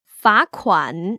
[fá//kuăn] 파쿠안